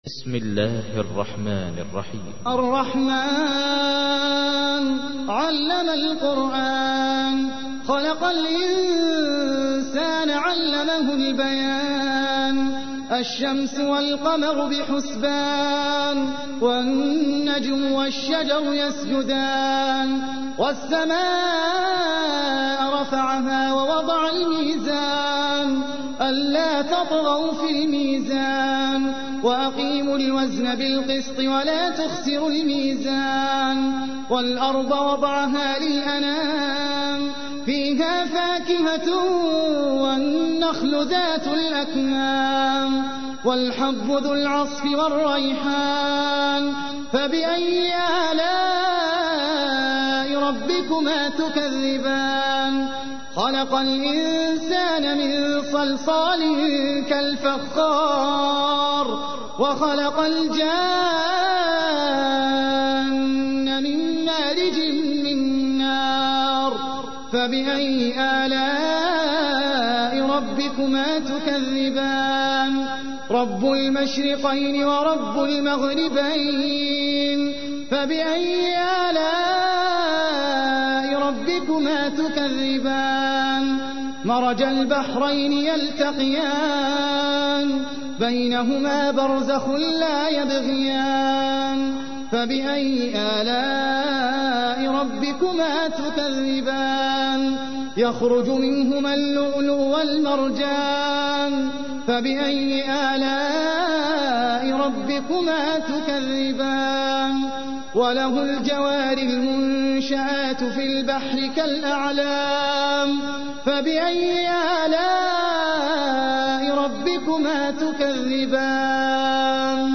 تحميل : 55. سورة الرحمن / القارئ احمد العجمي / القرآن الكريم / موقع يا حسين